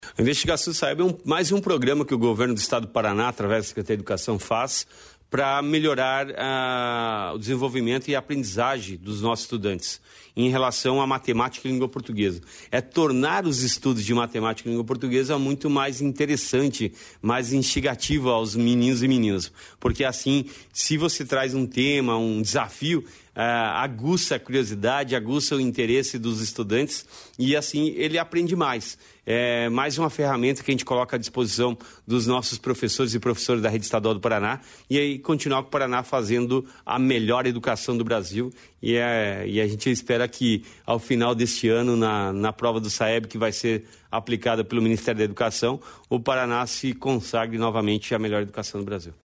Sonora do secretário da Educação, Roni Miranda, sobre o Evento Formativo Investigação Saeb